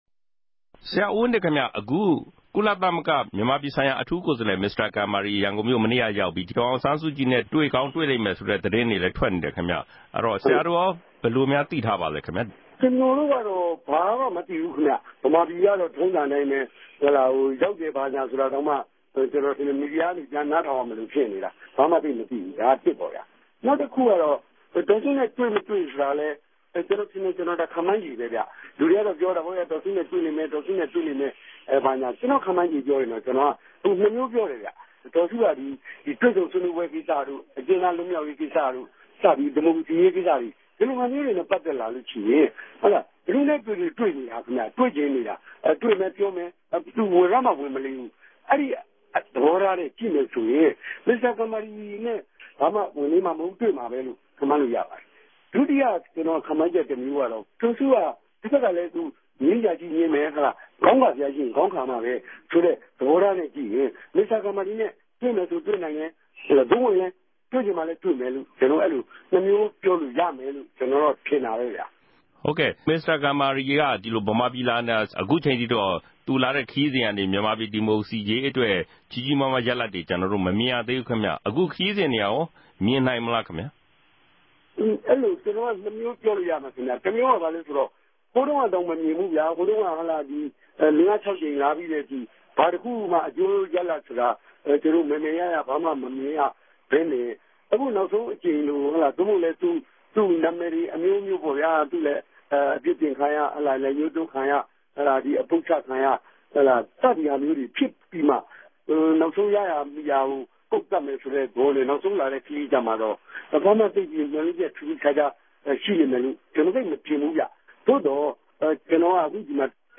သတင်းအူပည့်အစုံ။